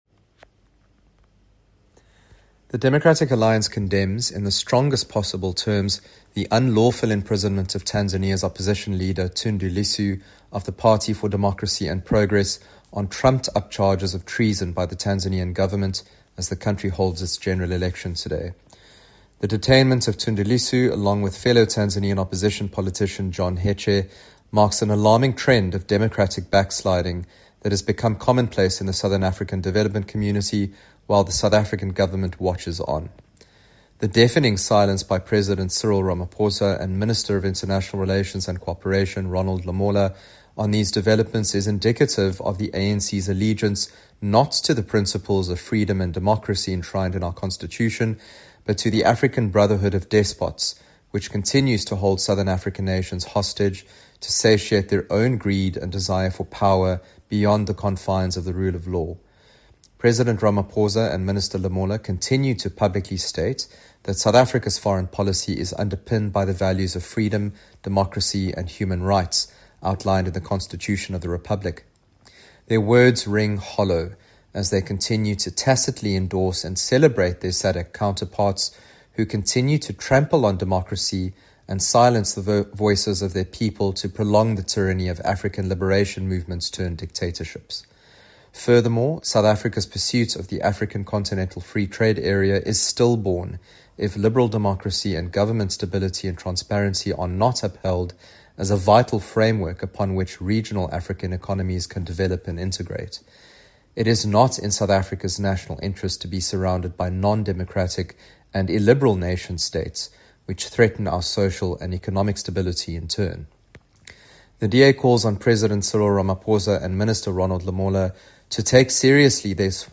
Soundbite by Ryan Smith MP.